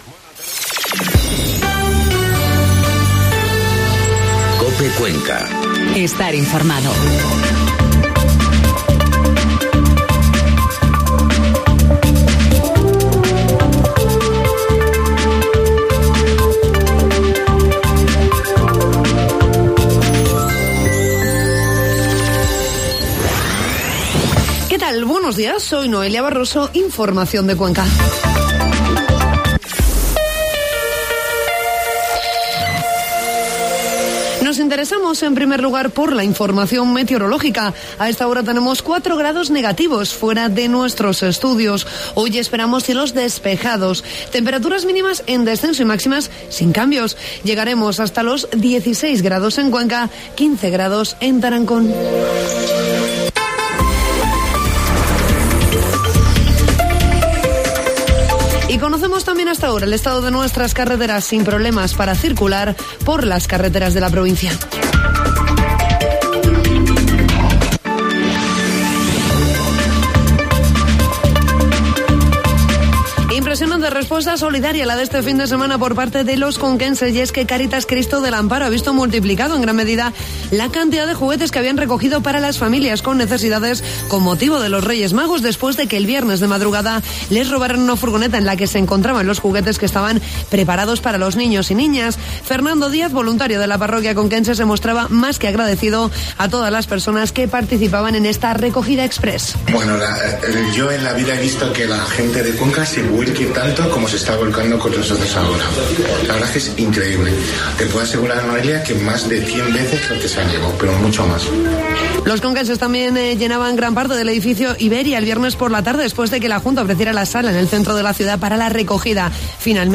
Informativo matinal COPE Cuenca 7 de enero